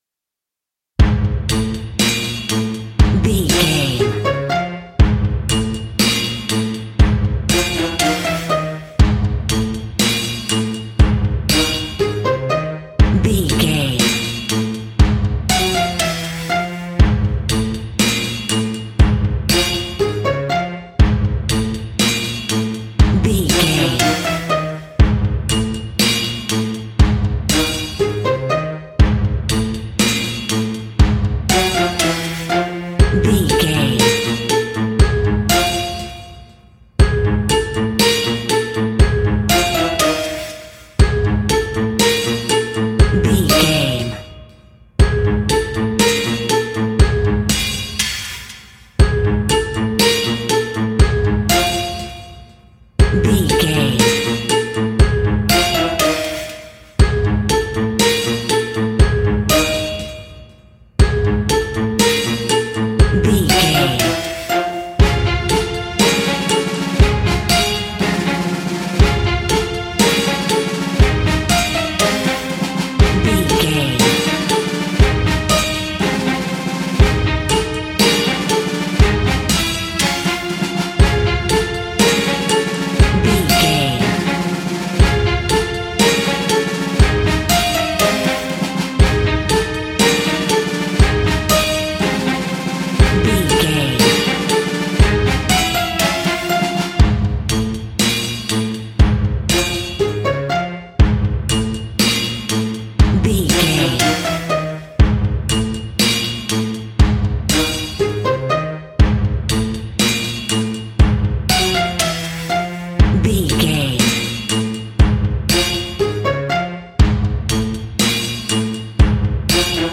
Aeolian/Minor
C#
Slow
scary
ominous
eerie
bouncy
percussion
brass
strings
horror music
royalty free horror music